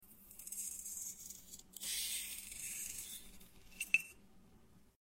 ASMR Crystal Pear Slice | sound effects free download
Oddly Satisfying Amethyst Fruit